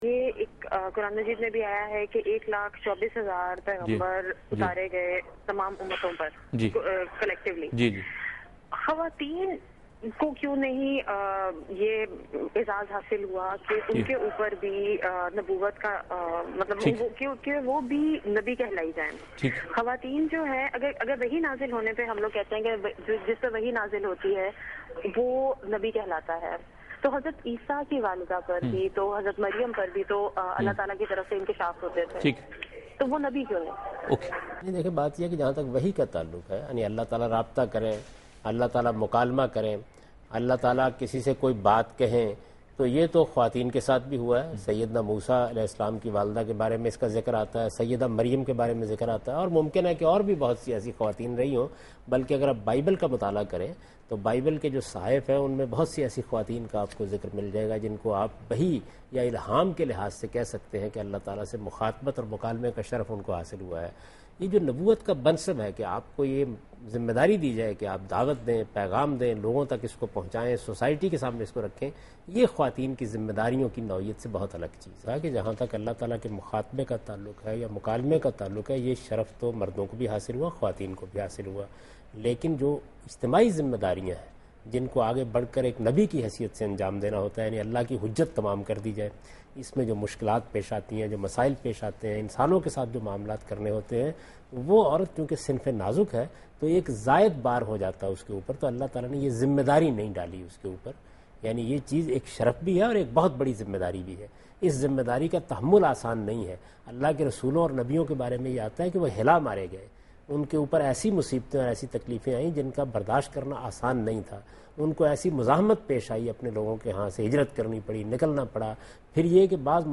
دنیا نیوز کے پروگرام دین و دانش میں جاوید احمد غامدی ”خواتین اور نبوت“ سے متعلق ایک سوال کا جواب دے رہے ہیں